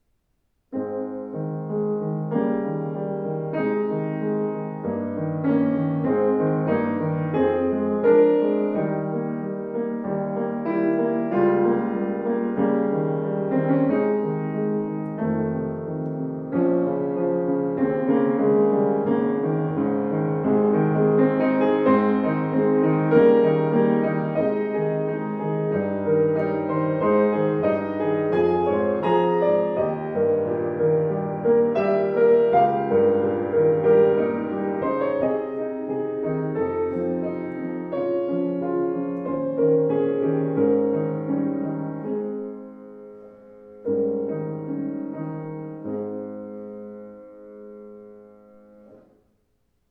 strahlender, gestaltungsfähiger Klang
Flügel